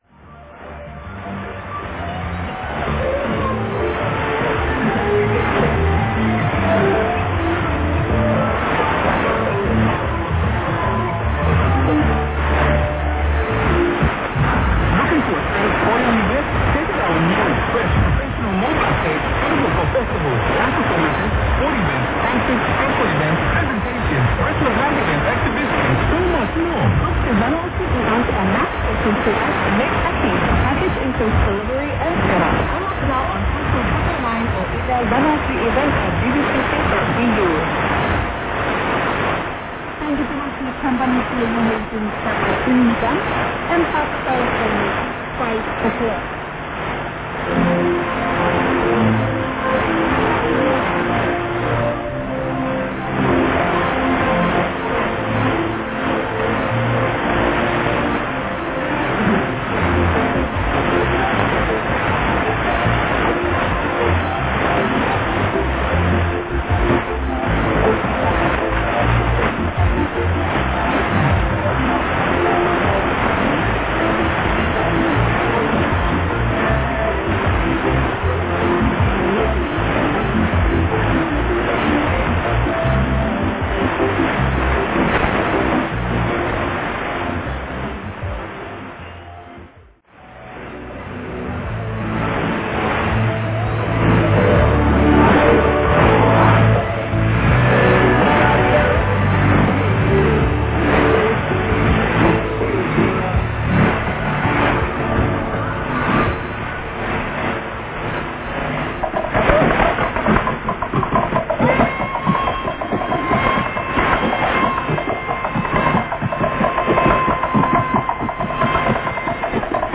ノイズの少なく野外受信では14時台から信号は弱いですが聞こえております。
時間が経つに連れて信号が強くなっている事がわかります。
<受信地：東京都江東区新砂 東京湾荒川河口 RX:ICF-SW7600GR ANT:AN-12>
※00:43-00:47 女性時刻アナウンス「Hem passes time, hemi 5 o'clock.」
※02:53-02:57 女性時刻アナウンス「Radio Vanuatu. Hem passes time, hemi 6 o'clock.」